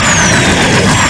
artillery_projectile_3.mp3